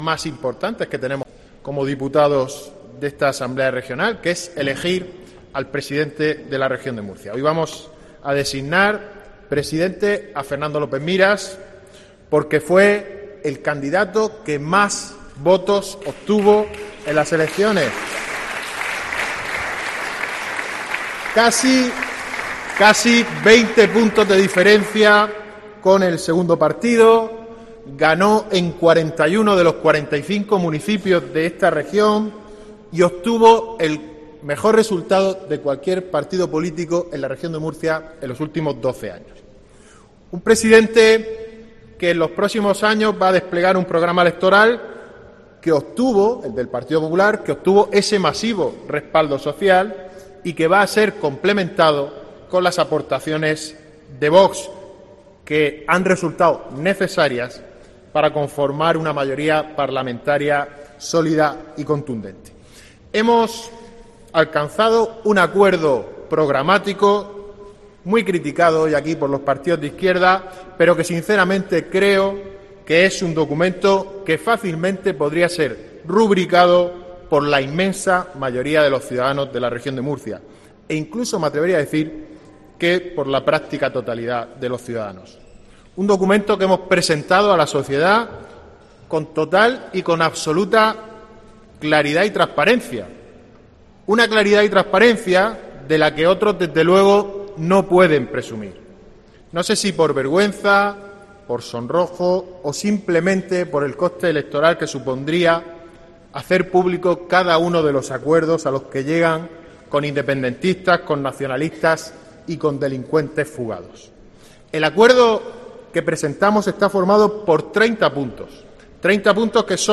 Joaquín Segado, portavoz del PPRM en la Asamblea Regional